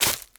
decorative-grass-03.ogg